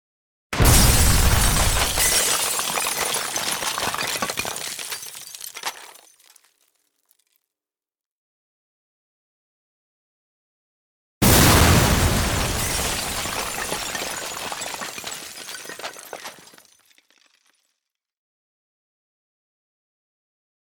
zvuk-vzryva-granata-so-steklami.mp3
Граната Звук взрыва